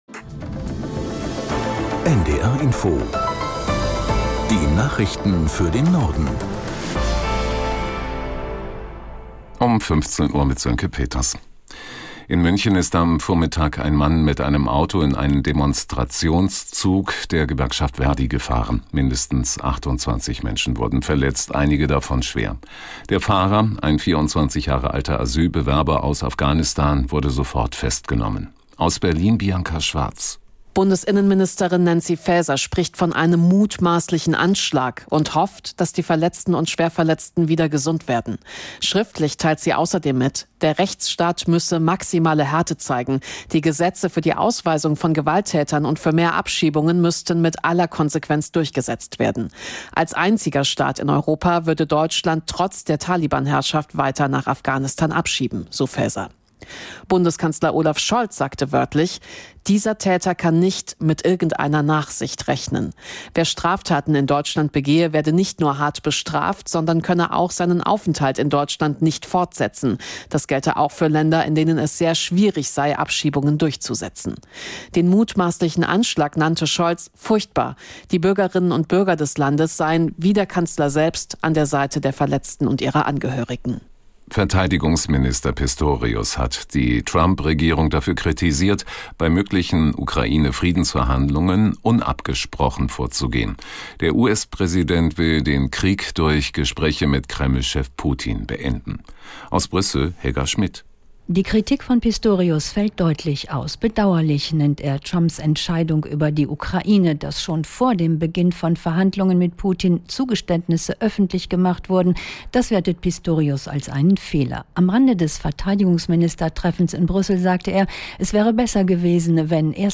Nachrichten - 13.02.2025